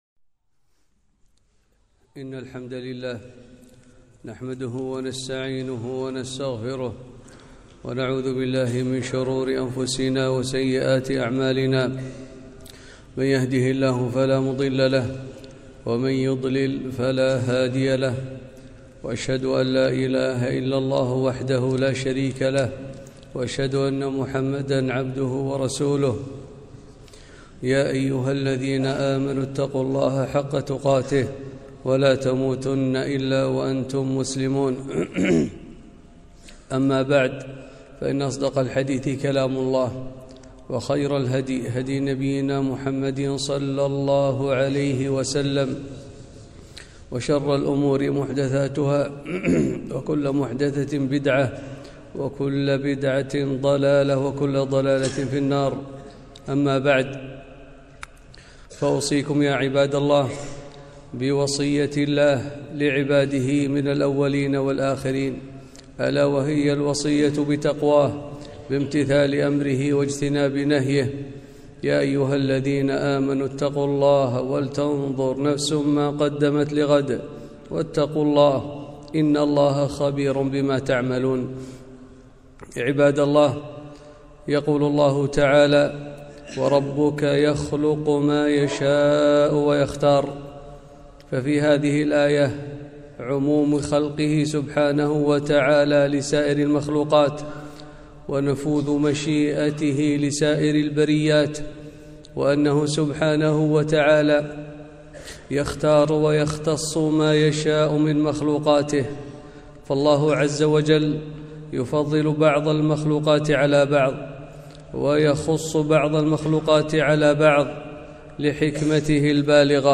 خطبة - شهر رجب وما فيه من البدع